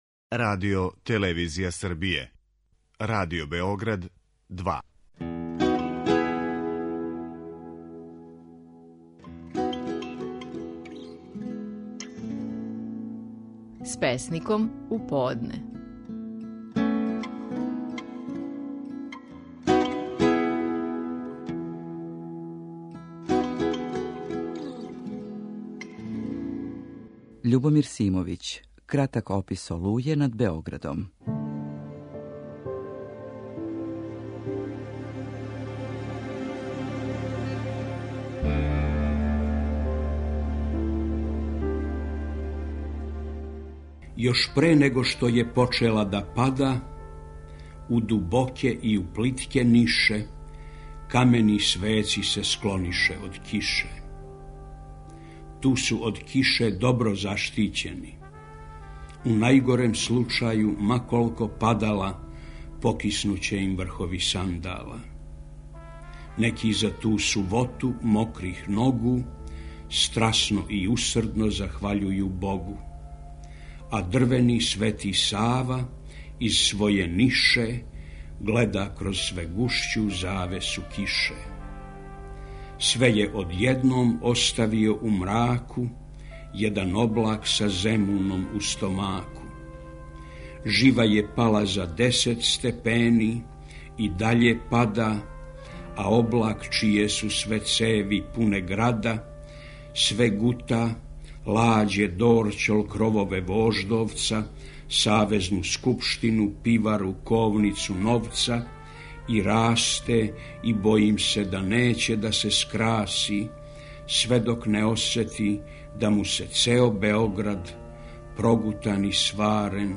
Стихови наших најпознатијих песника, у интерпретацији аутора.
Љубомир Симовић казује стихове песме „Кратак опис олује над Београдом".